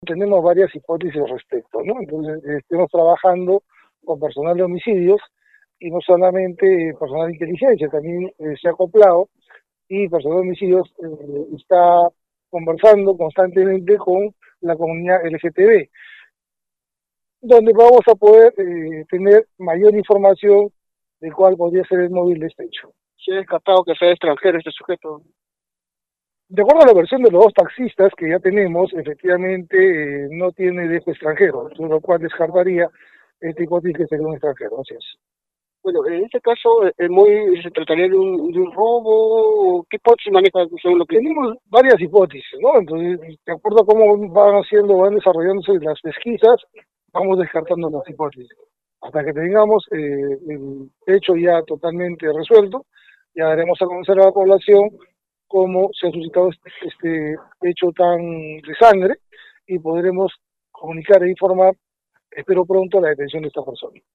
Las diligencias fueron confirmadas este miércoles 21 por el general PNP Arturo Valverde, asegurando que el trabajo está en marcha y de momento hay varias hipótesis; en tanto las investigaciones son desarrolladas por «el personal de Homicidios que está conversando constantemente con la comunidad LGBT donde vamos a poder tener mayor información de cual podría ser el móvil de este hecho», acotó.
declara-general.mp3